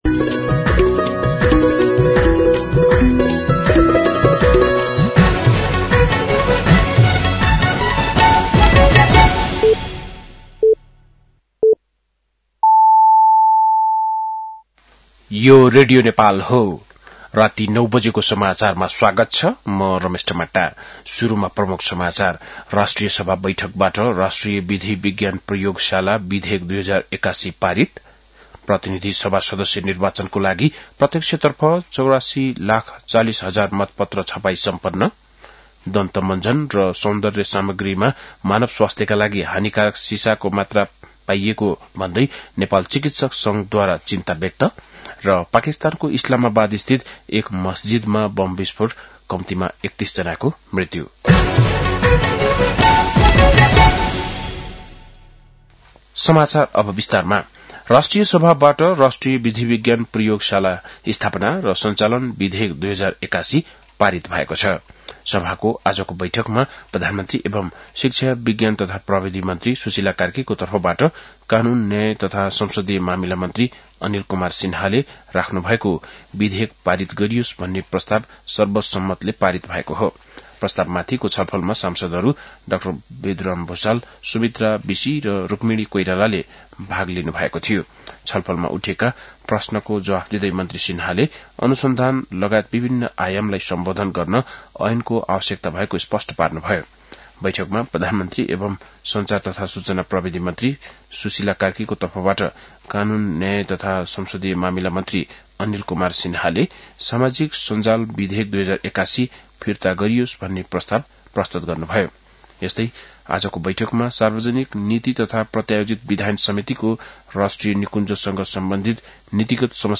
बेलुकी ९ बजेको नेपाली समाचार : २३ माघ , २०८२